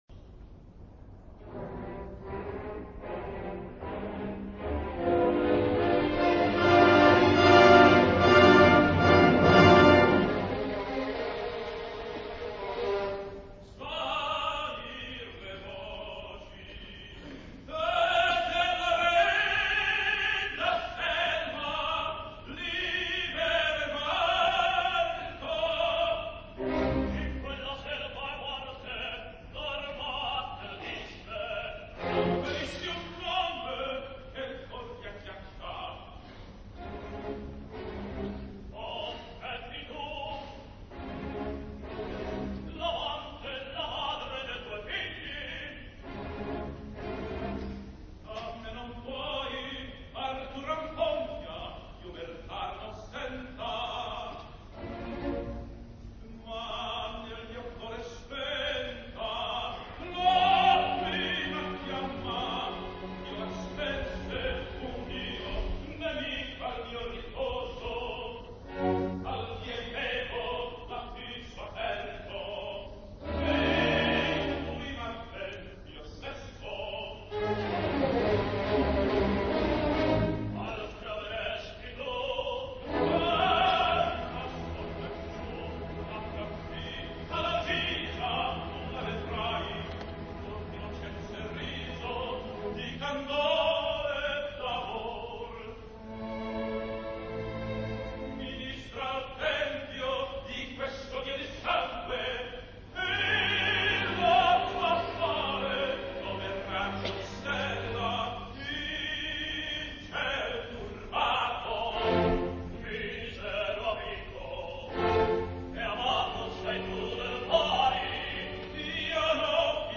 His was a true dramatic voice, but it was all sheer power, no ease, no brilliance, no color.